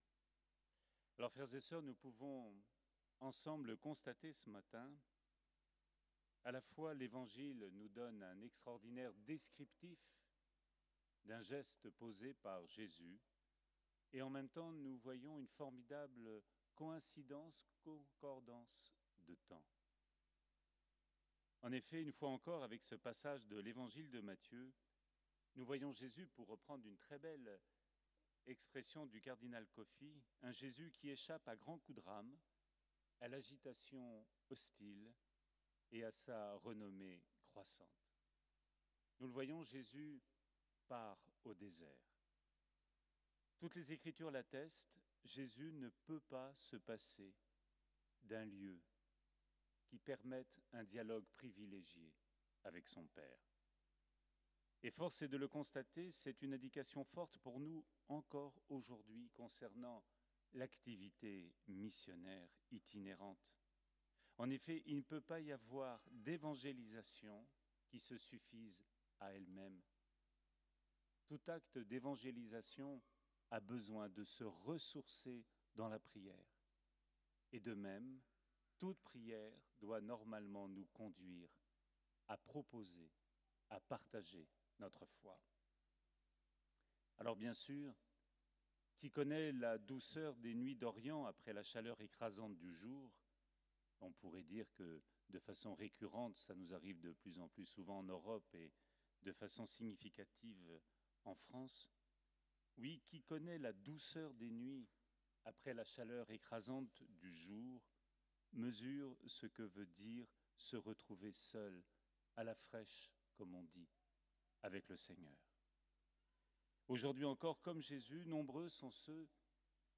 18ème Dimanche du Temps Ordinaire, année A